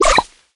spike_reload_03.ogg